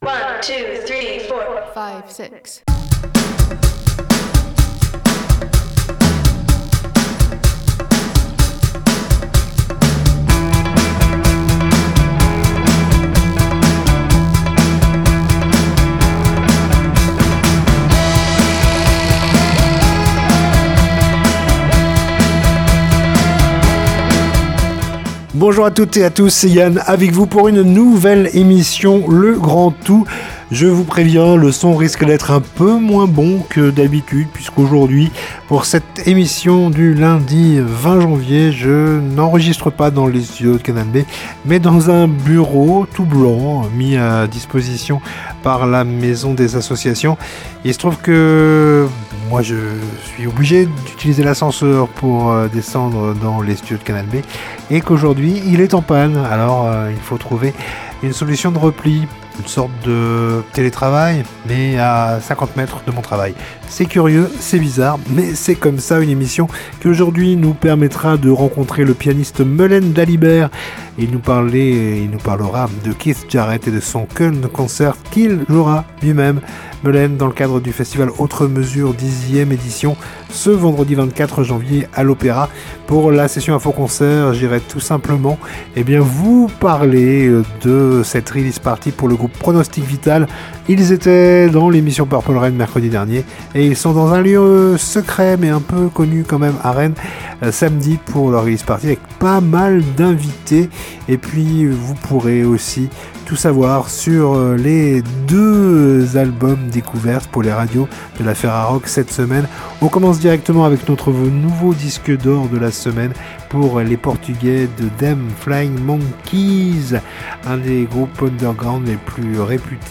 itv musique